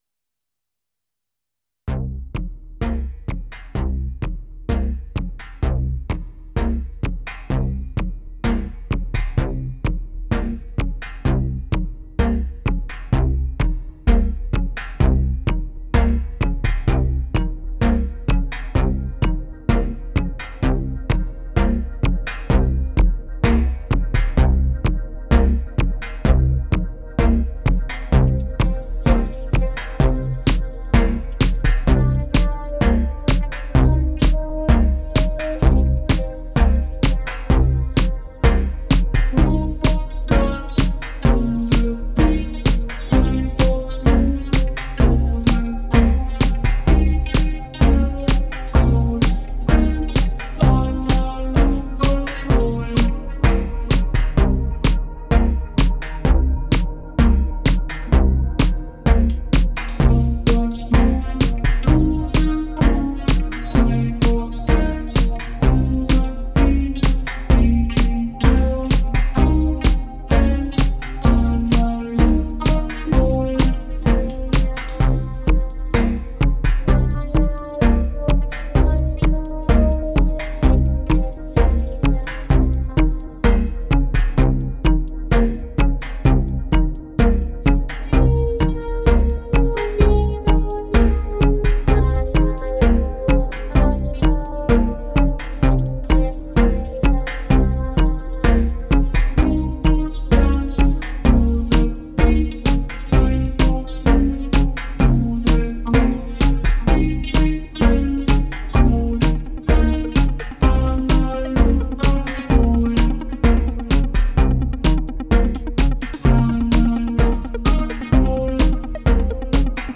a heavy dose of melodic, vocoded vocals
Dreamy, lush, and full of character.